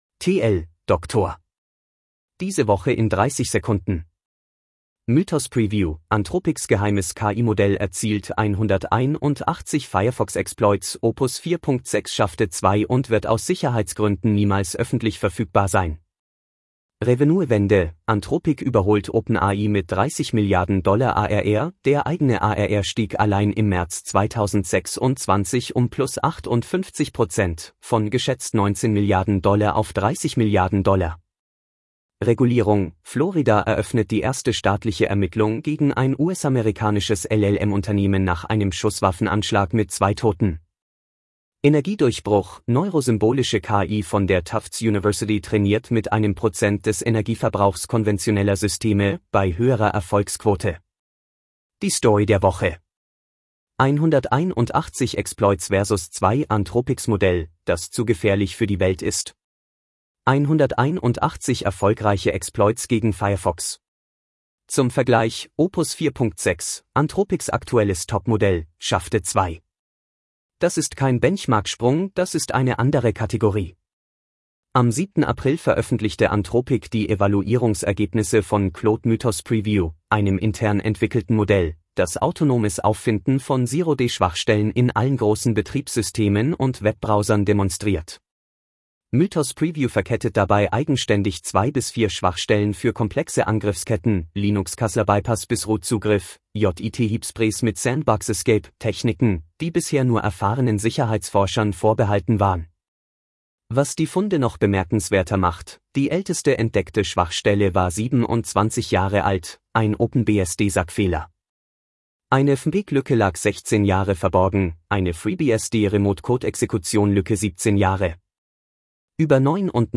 Vorgelesen mit edge-tts (de-DE-ConradNeural)
Enthält PerthNet-Audio-Watermark.